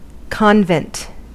Ääntäminen
IPA : /kɒn.vɛnt/